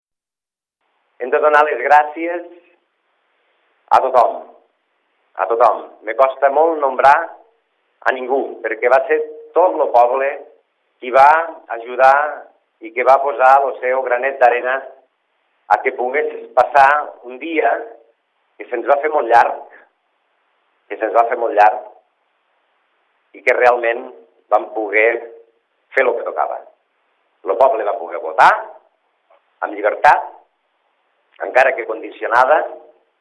Durant l’acte, l’alcalde Jordi Gaseni ha llegit un manifest com a resposta a la greu vulneració de drets i llibertats i també ha volgut agrair a tot el poble per la col·laboració durant el dia d’ahir.